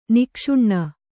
♪ nikṣuṇṇa